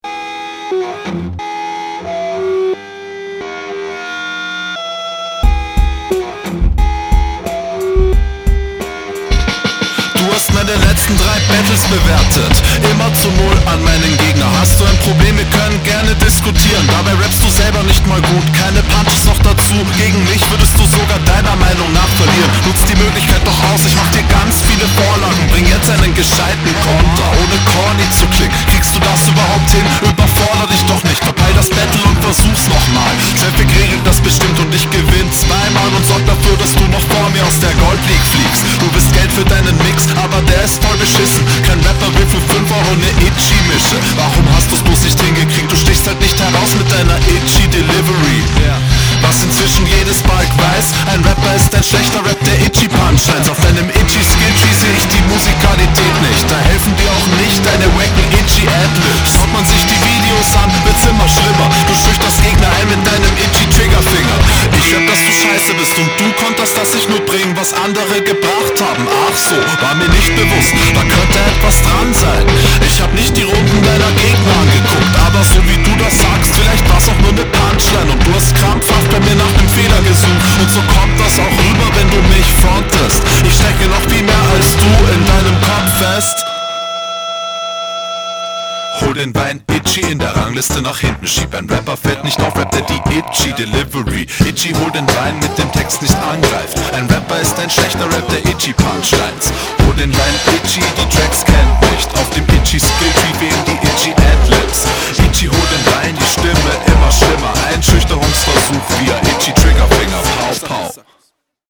Stranger Beat aber gyle, Mixing gyle, ich mag das dreckige distortete, gyle bars, man merkt …